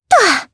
Sonia-Vox_Landing_jp.wav